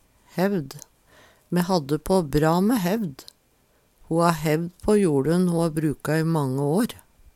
hevd - Numedalsmål (en-US)